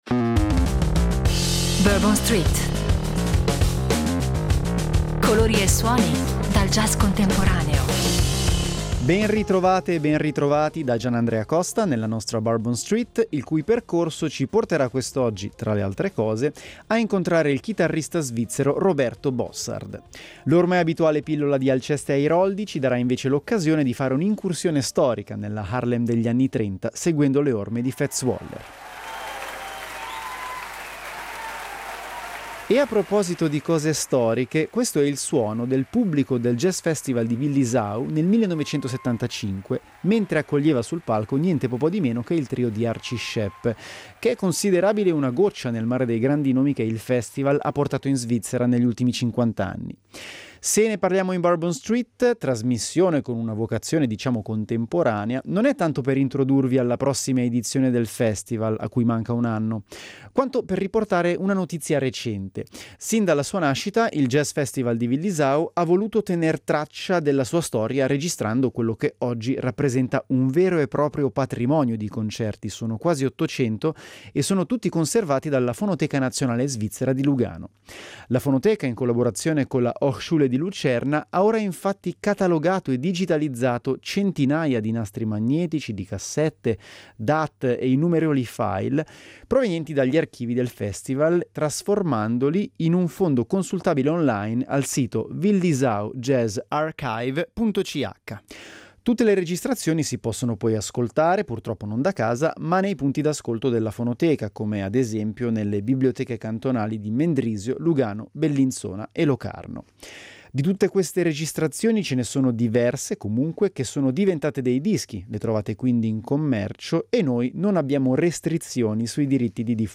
Vecchio e nuovo insieme, spinta verso il futuro coniugata con il più totale rispetto della propria storia: colori e suoni dal jazz contemporaneo insomma.